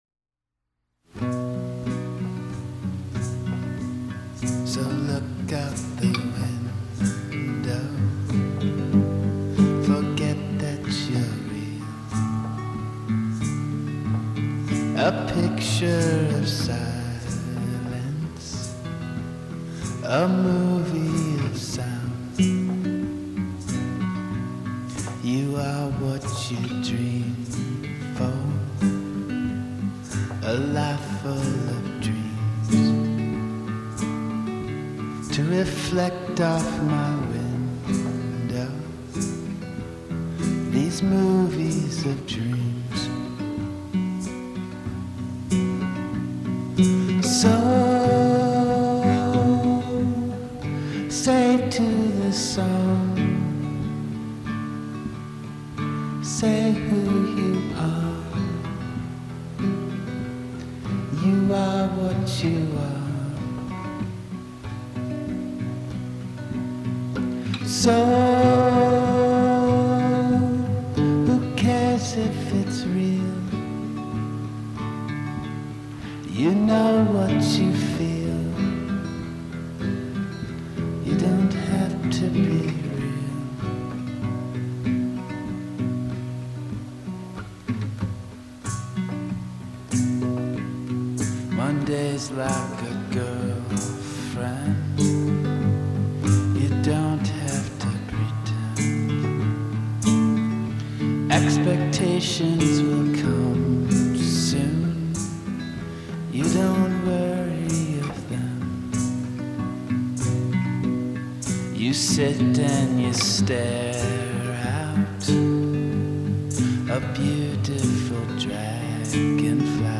Do electric folk dream of opium sheep?
lo-fi fidelity